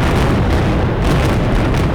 boom.ogg